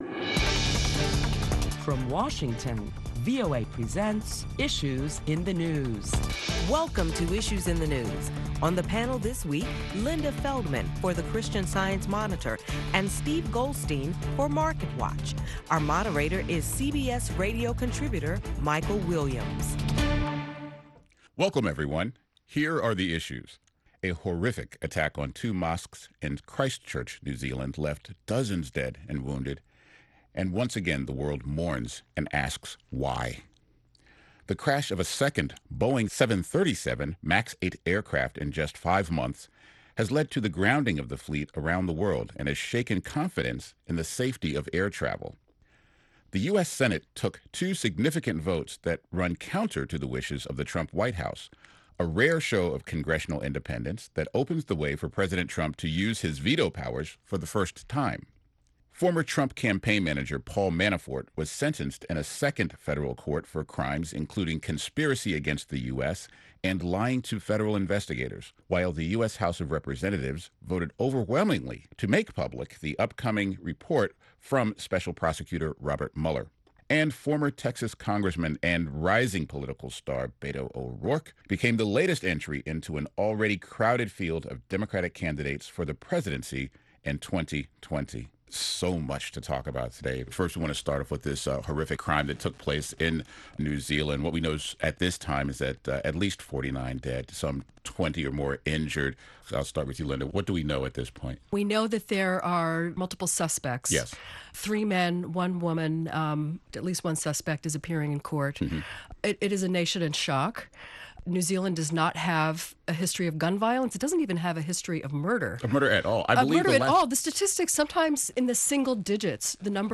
Tune in to a panel of prominent Washington journalists as they deliberate the week's headline news beginning with the mass shooting in two New Zealand mosques killing 49 people.